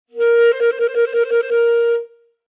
Descarga de Sonidos mp3 Gratis: saxofon 22.